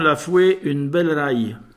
Localisation Sainte-Christine
Catégorie Locution